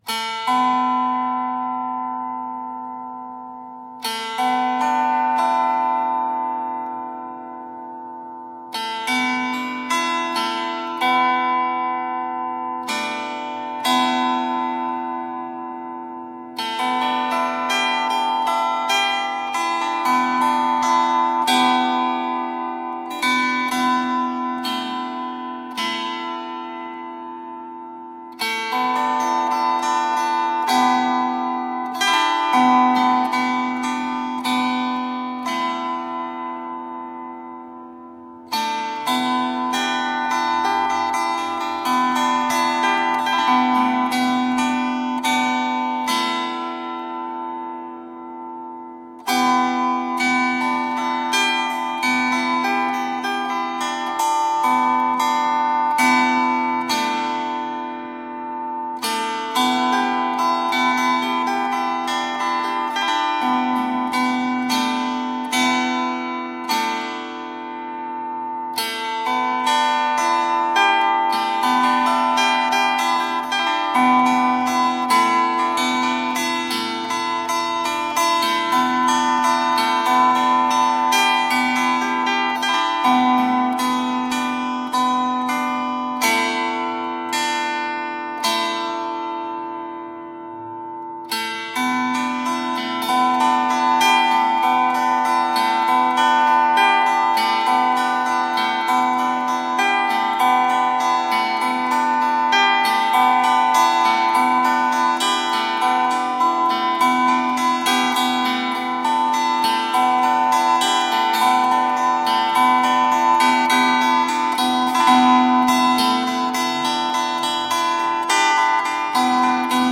Medieval and middle eastern music.
improvisation for Welsh plucked cruit